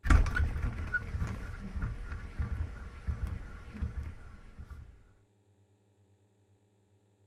GlobeSpin.wav